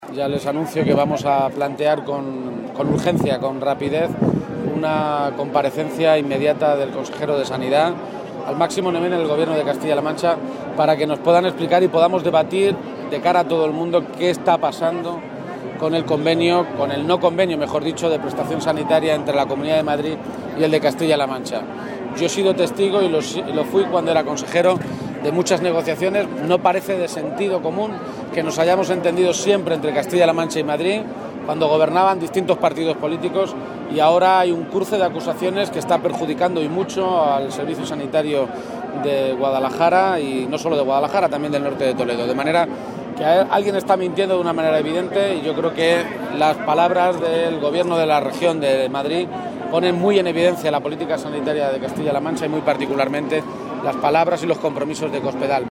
García-Page en las fiestas de Guadalajara